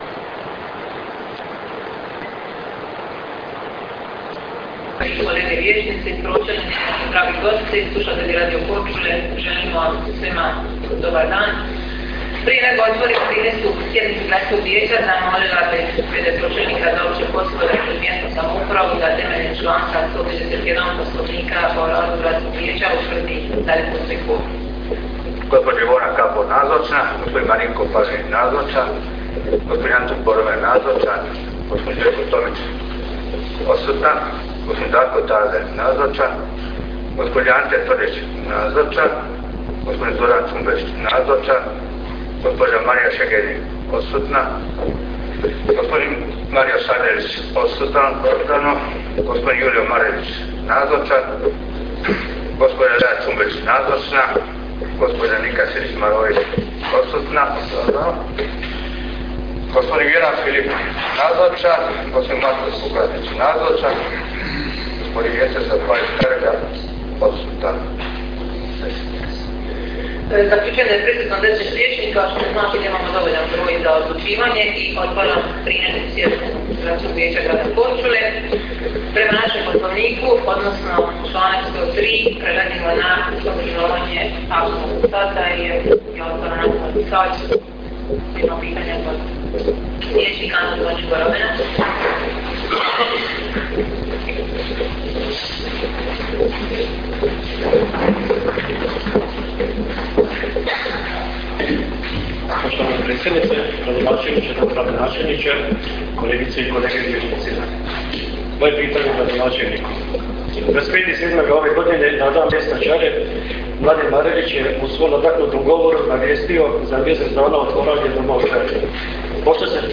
Prvi dio sjednice održan je 17. rujna (srijeda) 2014. godine, s početkom u 18.07 sati, u Gradskoj vijećnici u Korčuli.
Prvom dijelu sjednice je predsjedala Ivona Kapor – predsjednica Vijeća.